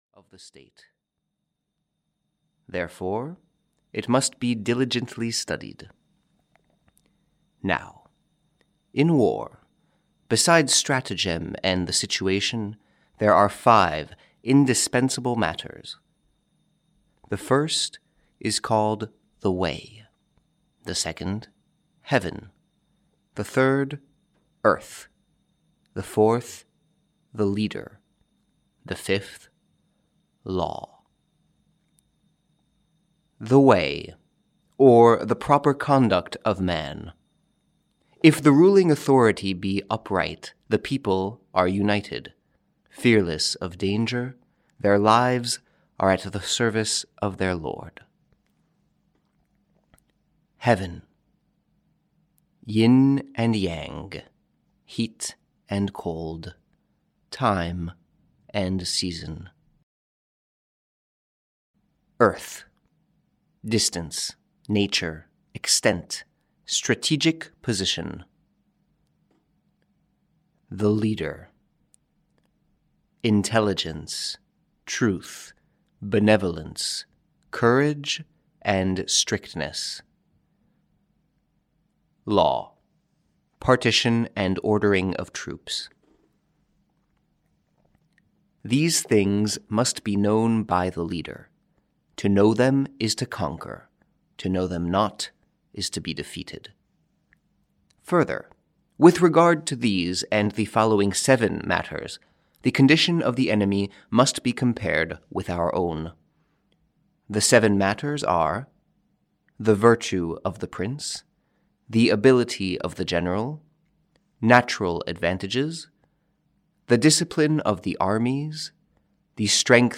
The Art of War (EN) audiokniha
Ukázka z knihy